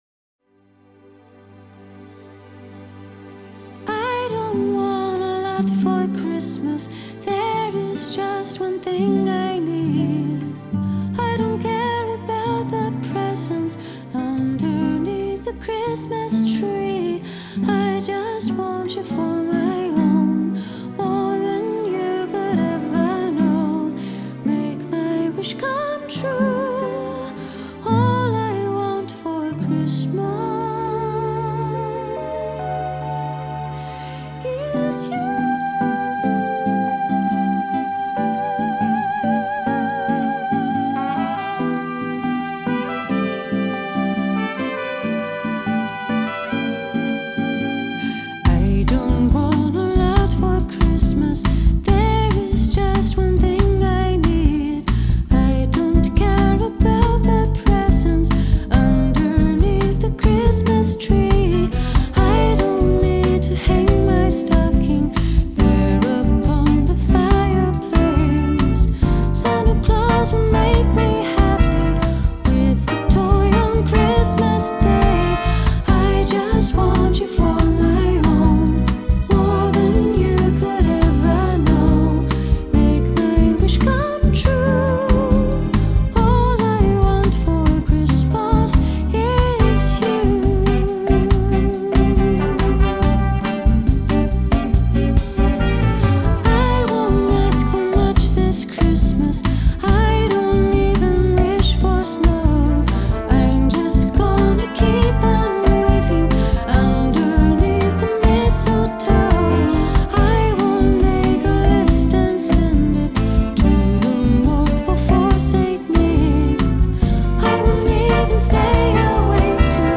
Genre: Bossa Nova Christmas